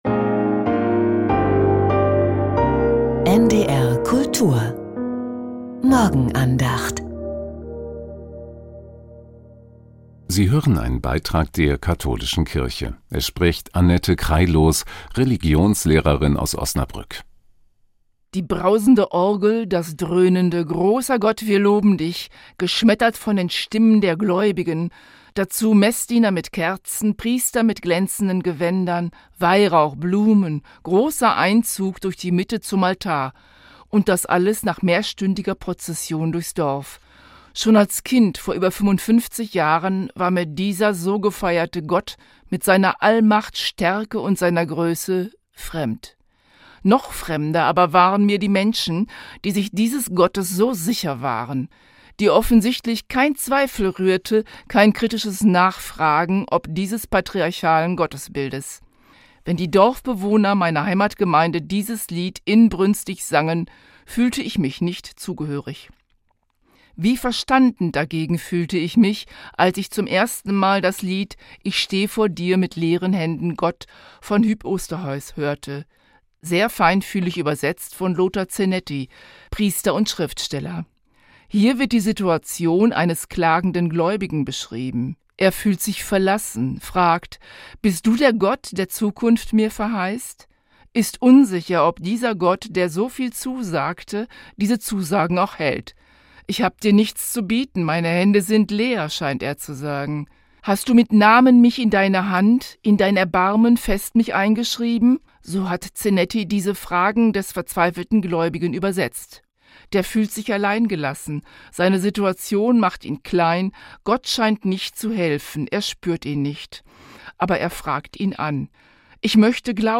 Die Morgenandacht